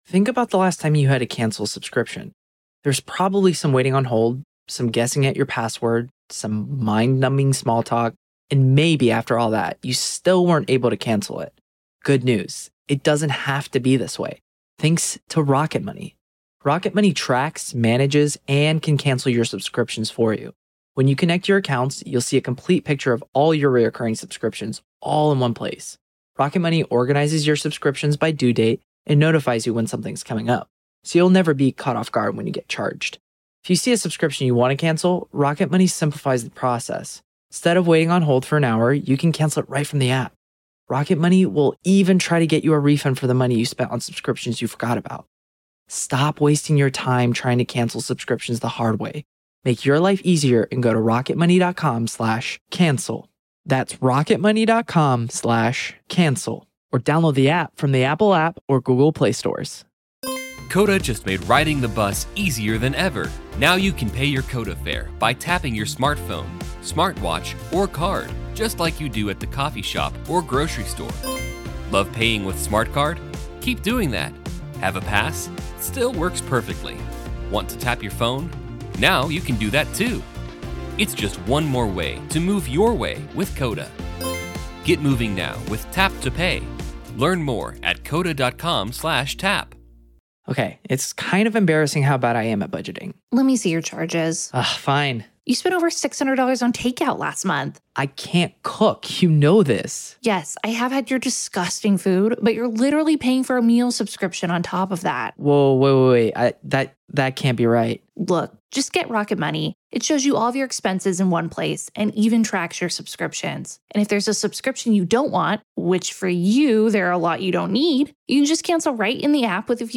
The Trial Of Alex Murdaugh | FULL TRIAL COVERAGE Day 16 - Part 3